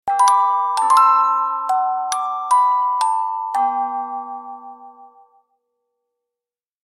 جلوه های صوتی
دانلود صدای ساعت 26 از ساعد نیوز با لینک مستقیم و کیفیت بالا